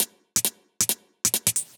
Index of /musicradar/ultimate-hihat-samples/135bpm
UHH_ElectroHatD_135-03.wav